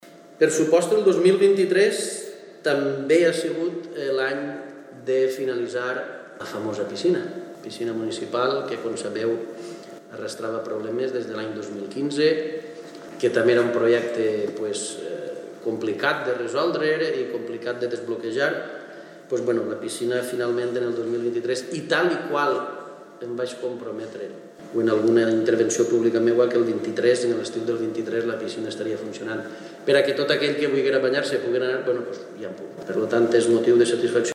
Hoy ha sido el turno de Teulada Moraira, donde su alcalde, Raúl Llobell, ha organizado un desayuno con la prensa para repasar los proyectos llevados a cabo así como poner sobre la mesa los que están por venir.